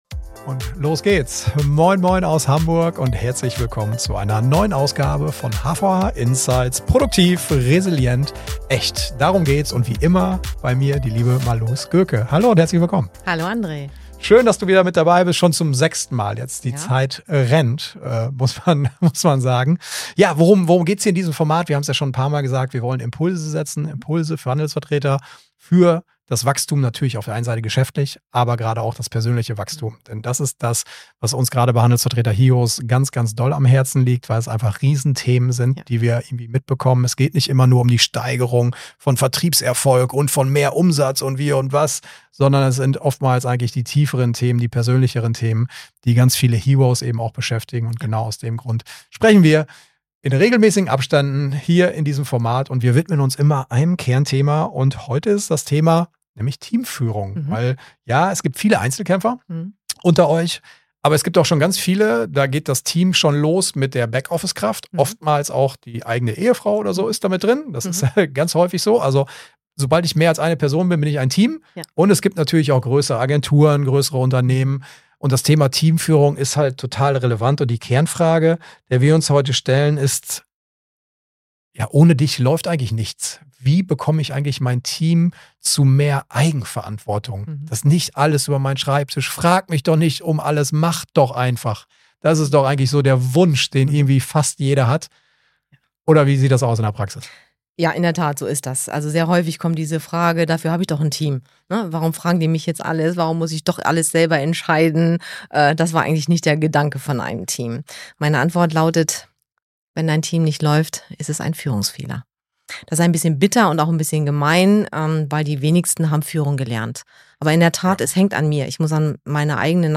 Talk Podcast